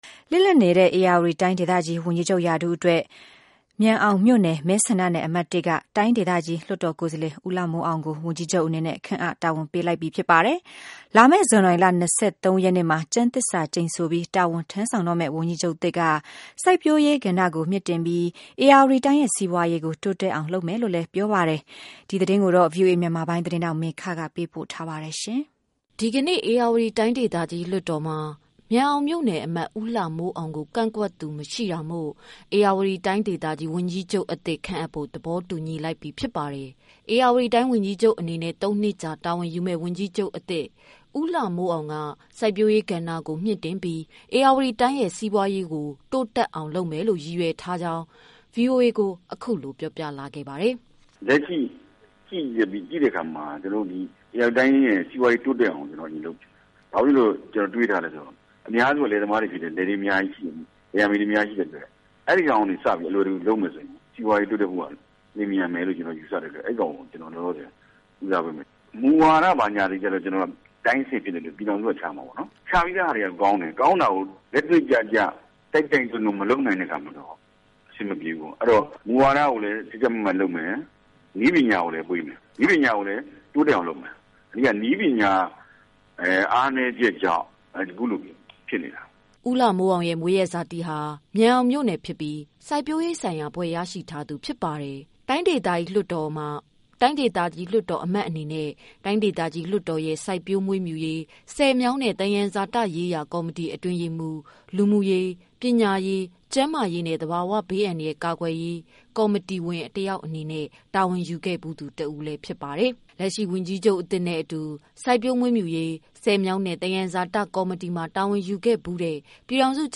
ဧရာဝတီတိုင်း ဝန်ကြီးချုပ် အသစ် ဦးလှမိုးအောင်နဲ့ ဆက်သွယ်မေးမြန်းချက်အပြည့်အစုံ